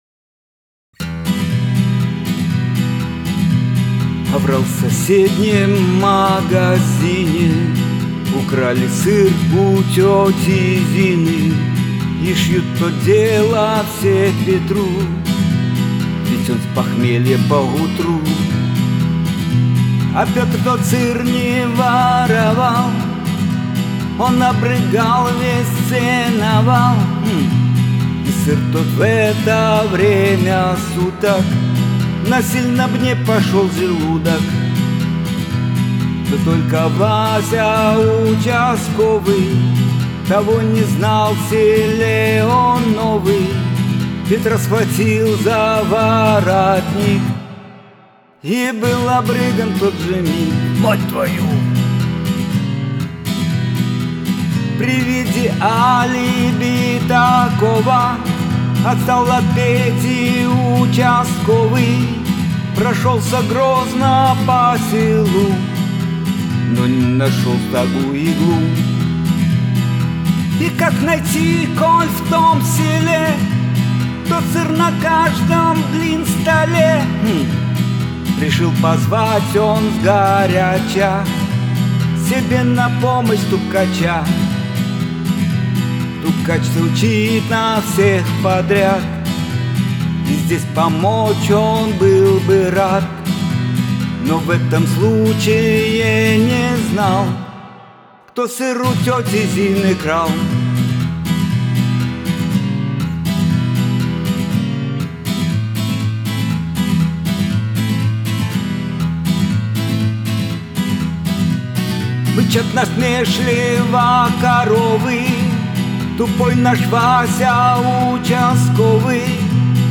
Юмористическая песня
гитара